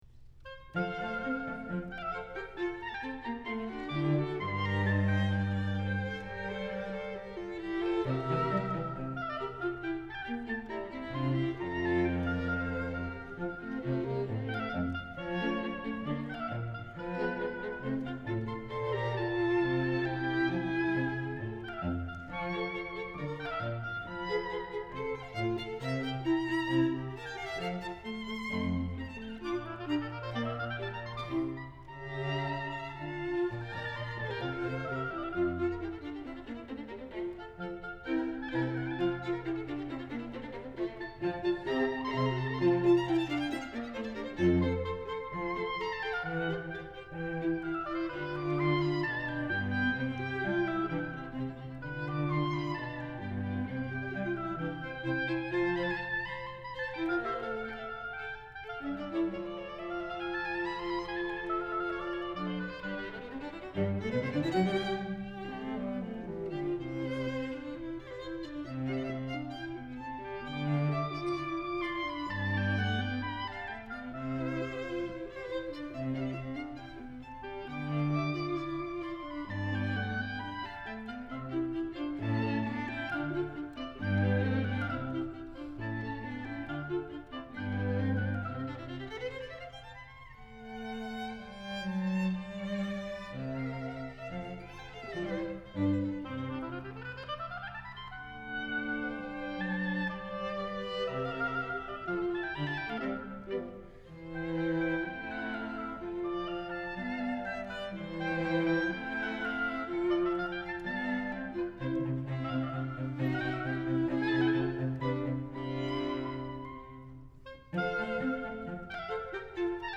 Time as the Subject and Substance | Forums and Concerts | Interdisciplinary Approaches to Musical Time | Music and Theater Arts | MIT OpenCourseWare
oboe
violin
viola
violincello